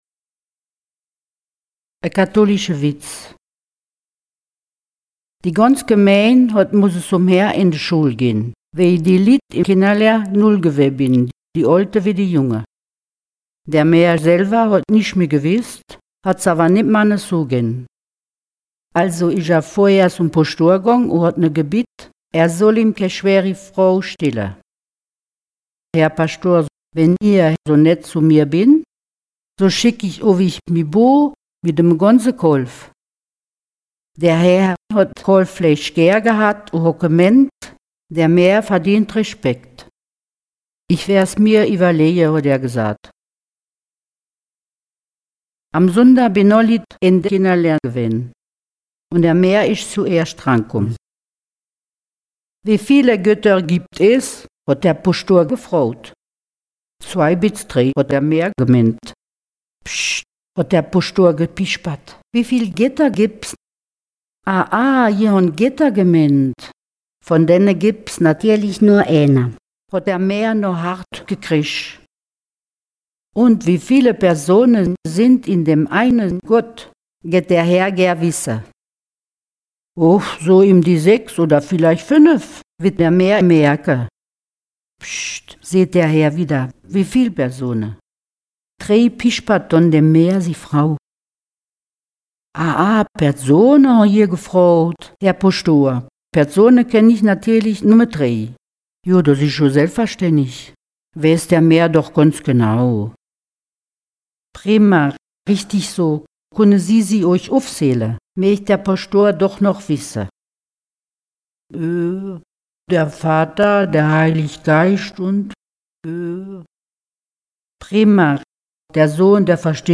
Contes et récits en ditsch enregistrés dans les communes de Linstroff, Grostenquin, Bistroff, Erstroff, Gréning, Freybouse, Petit Tenquin-Encheville, Petit Tenquin et Hellimer-Grostenquin.
Petit Tenquin-Eincheville